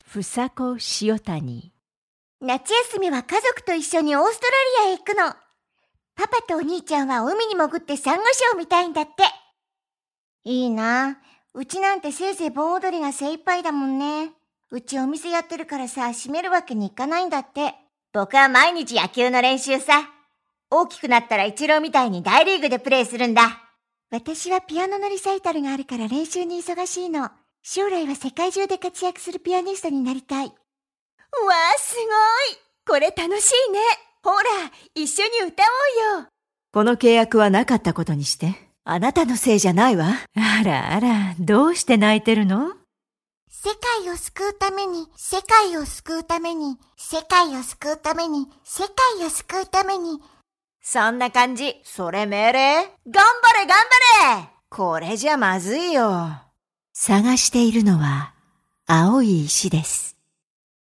JP FS VG 01 Videogames Female Japanese